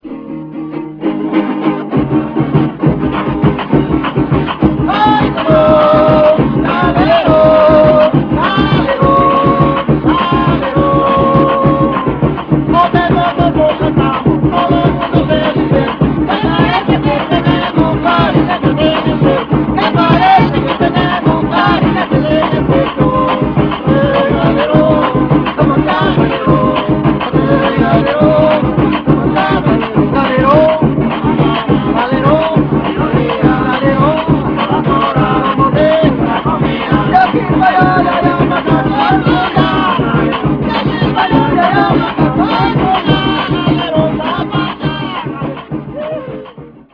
TAMUNANGUE
La primera parte se inicia con un pequeño estribillo que incluye la voz del galerón compuesta de tres ó o bien seis versos pentasílabos o más breves, los cuales se repiten o pueden ser diferentes entre sí y se inician con un tarareo.
La expresión melódica presenta la repetición de un pequeño motivo durante varios compases. Se inicia con las tres notas superiores del acorde. El motivo inicial se marca en 3/4 siguiendo el ritmo de acompañamiento. Los instrumentos cordófonos marcan el ritmo a través de tres tiempos sobre acordes de Tónica y Dominante; o de Tónica, Sub dominante y Dominante.